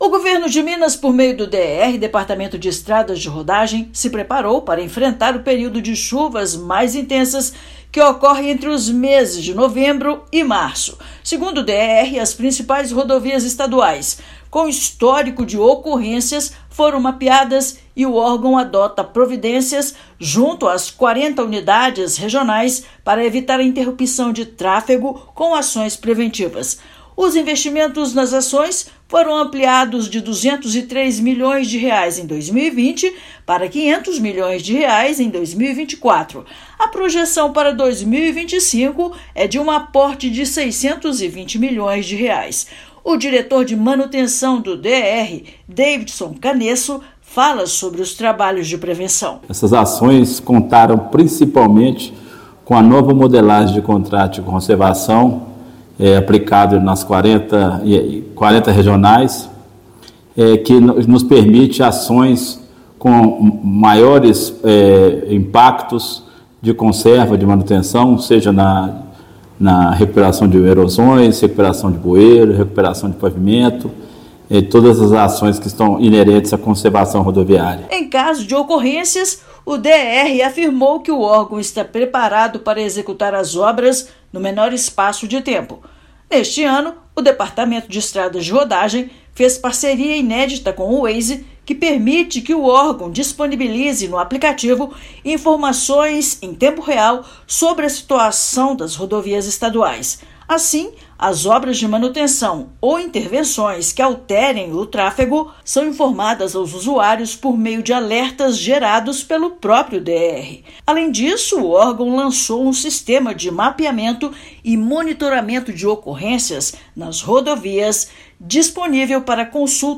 Além de investimento de R$ 500 milhões em conservação e manutenção das estradas estaduais, plano de ação inclui equipes de prontidão em todas regiões. Ouça matéria de rádio.